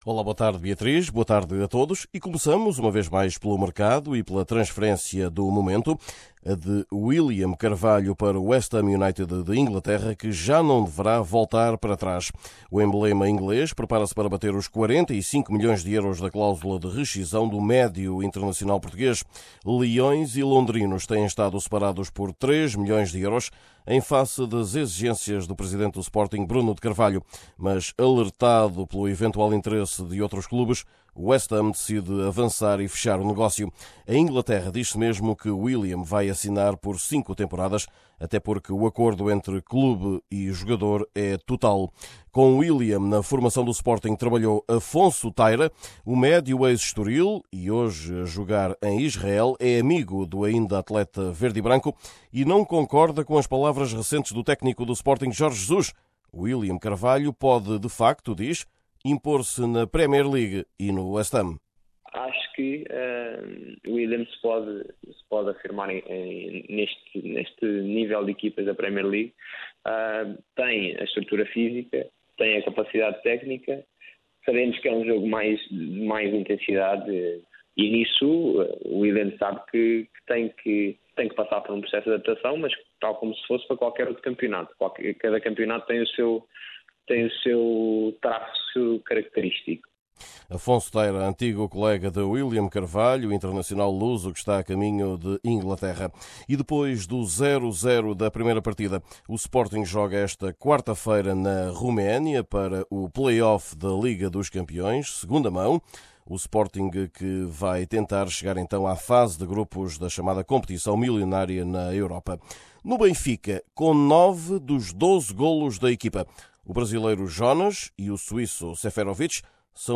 Neste boletim semanal, falamos ainda das provas das UEFA ou da Liga portuguesa.